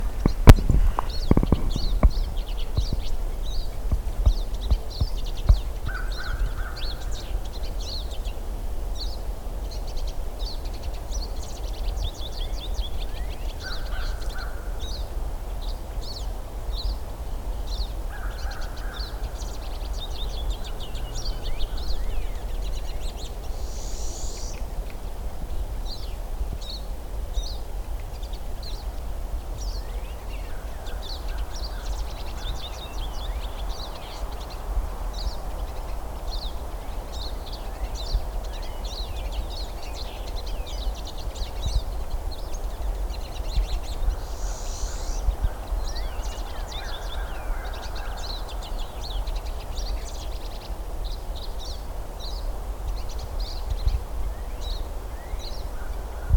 PINE SISKIN
Another highlight was hearing the jubilant song as they prepared to breed,
recorded here at the Waterloo State Game Area, Washtenaw County.